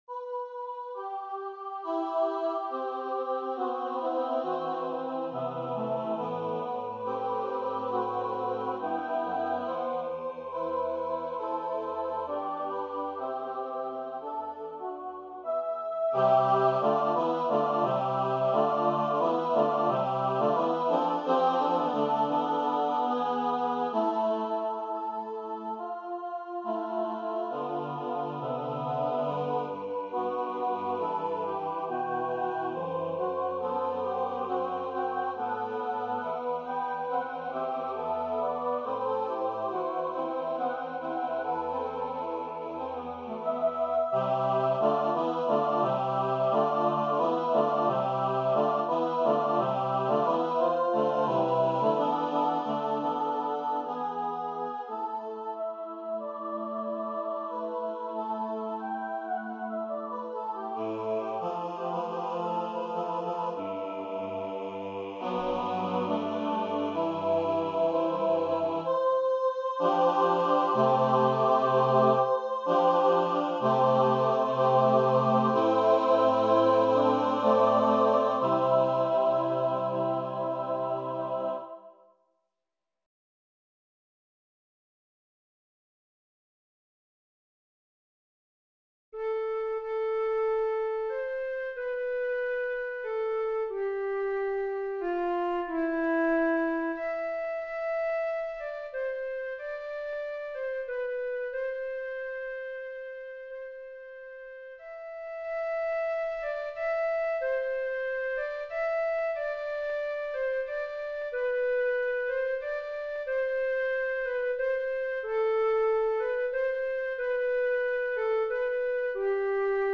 Title: Totentanz Composer: Hugo Distler Lyricist: Angelus Silesius/Lübecker Totentanzcreate page Number of voices: 4vv Voicing: SATB Genre: Sacred, Unknown
Language: German Instruments: A cappella
A cappella choral settings of 14 separate proverbs
alternating with a theme and variations for flute solo and with spoken texts